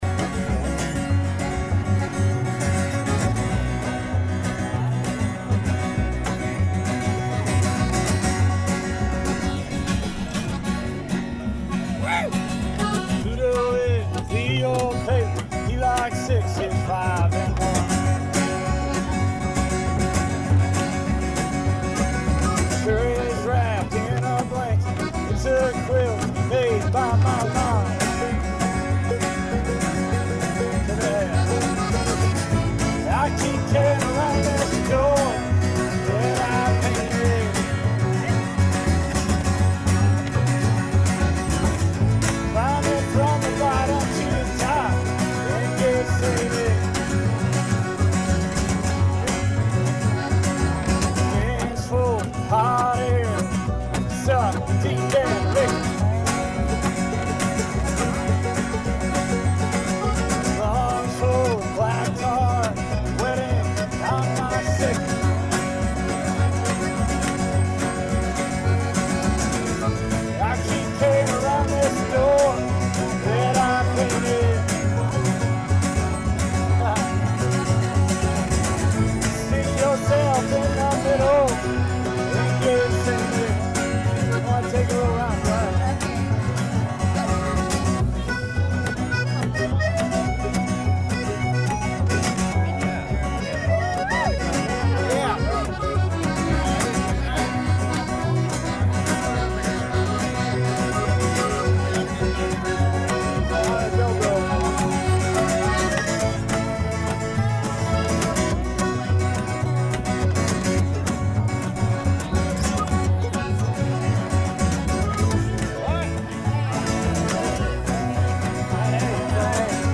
We crossed paths and setup the jam right where we met at the crossroads by the "top of the world" bus.
The crowd began to form and before long we were stirring up folk roots with the aid of the players in the campground!
and many others on banjo, mandolin, fiddle, guitars, and so on...Please email with any more information you may have to add...